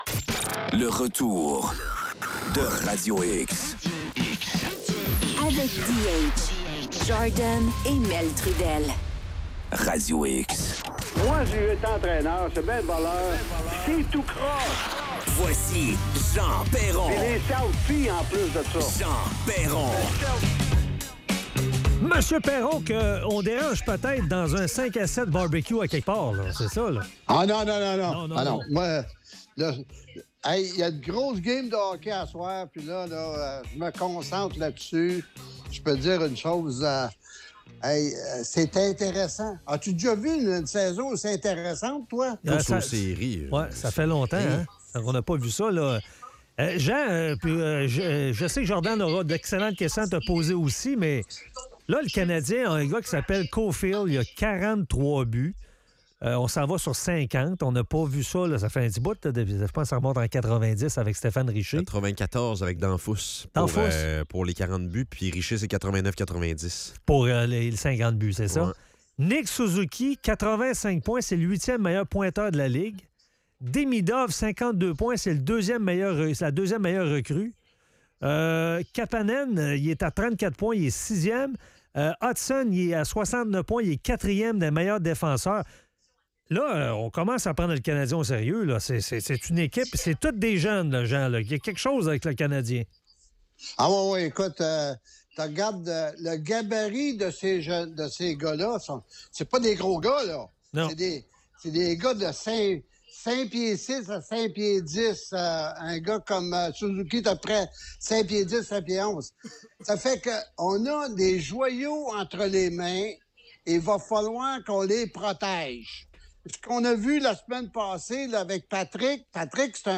La chronique de Jean Perron.